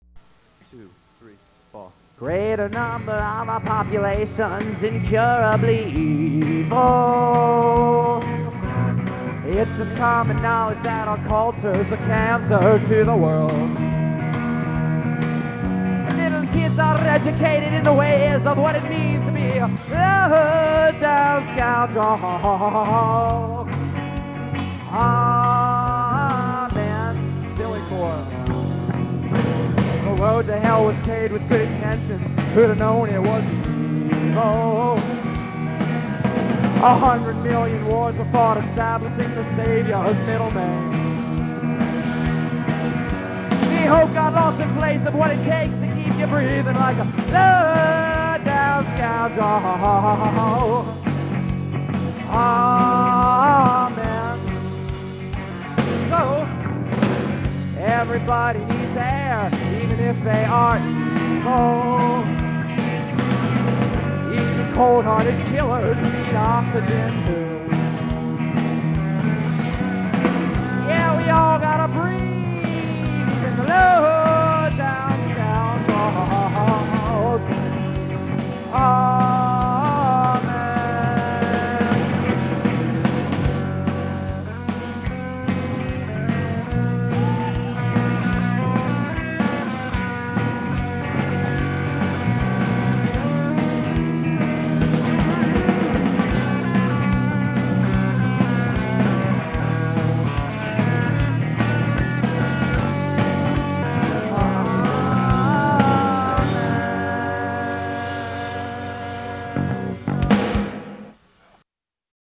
I remember some cheering after each song.